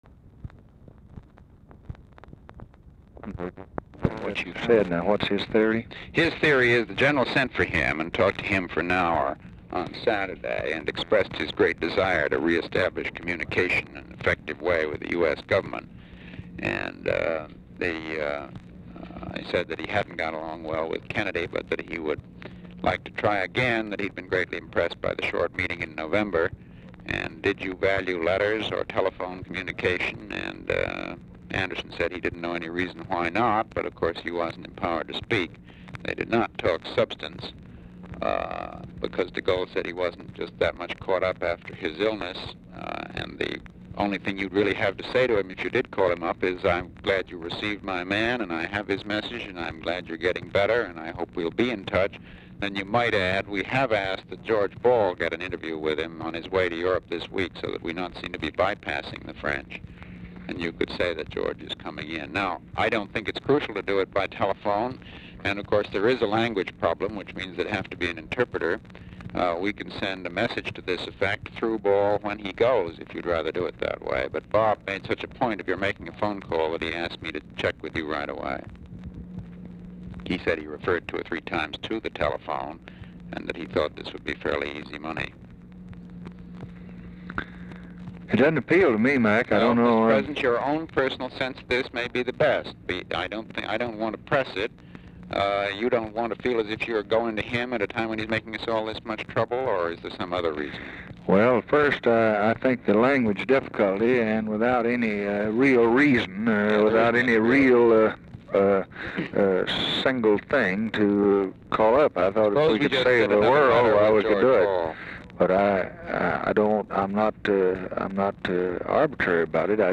Telephone conversation # 3601, sound recording, LBJ and MCGEORGE BUNDY, 6/1/1964, 1:04PM | Discover LBJ
Format Dictation belt
Location Of Speaker 1 Oval Office or unknown location
Specific Item Type Telephone conversation